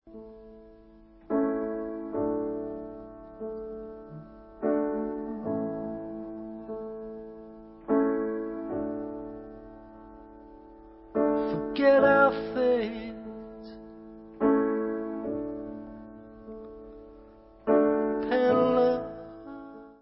Alternativní hudba